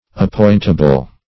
Appointable \Ap*point"a*ble\, a. Capable of being appointed or constituted.